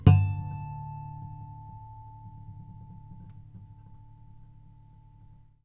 strings_harmonics
harmonic-09.wav